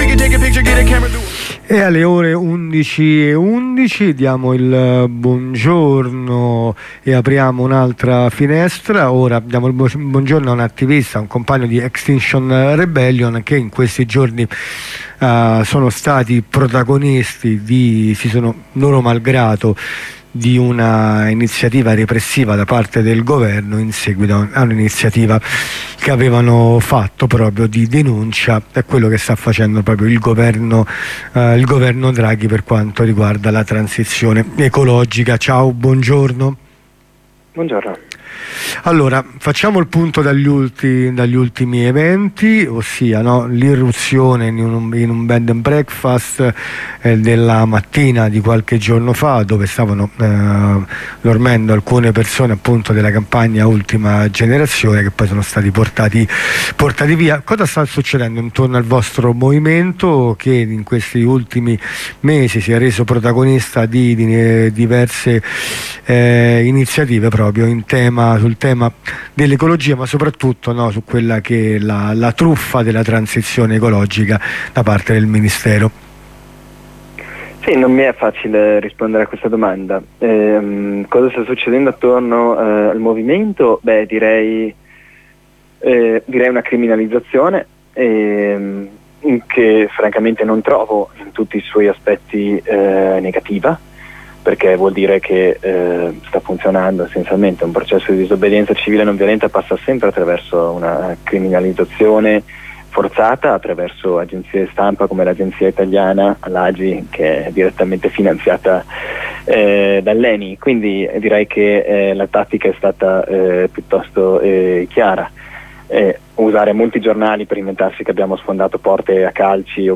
Ne parliamo con un attivista di ER.